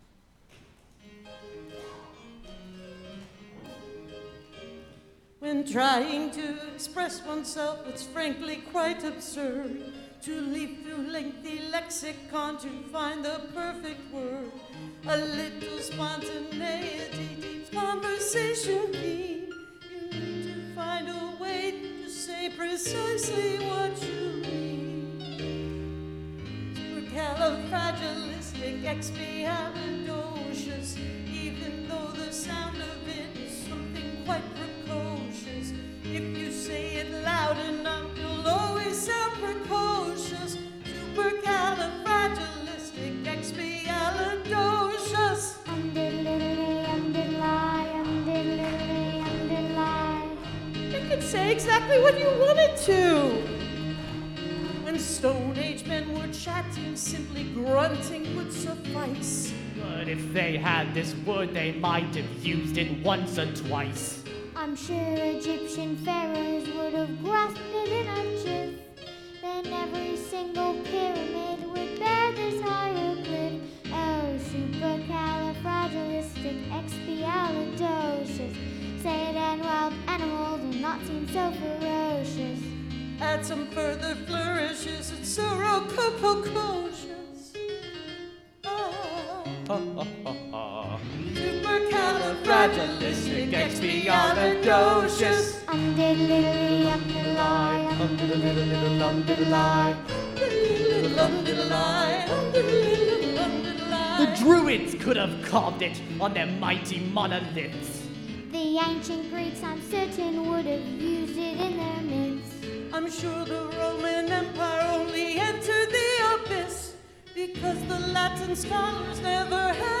oakhurst baptist church - decatur, georgia (all together now!: a global event celebrating local theatre) (in person and virtual)
(captured from the live stream)